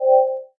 ui_buttonhover.wav